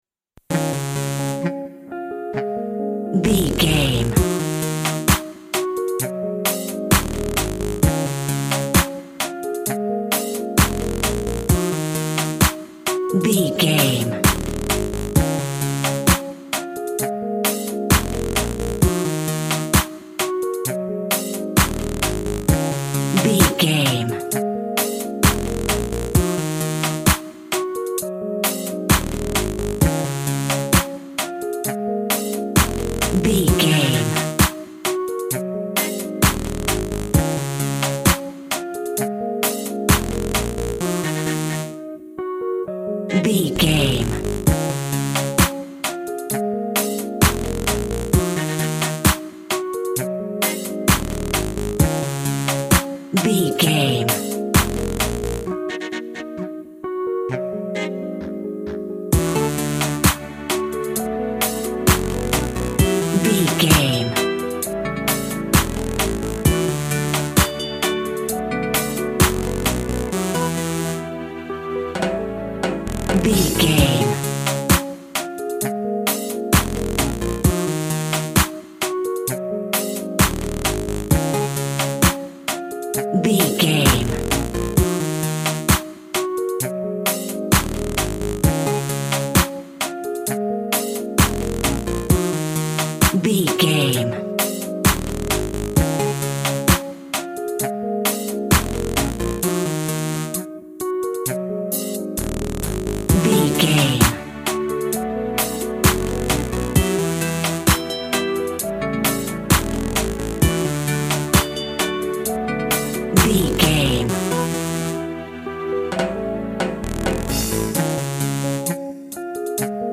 Also with small elements of Dub and Rasta music.
Ionian/Major
tropical
drums
bass
guitar
piano
brass
steel drum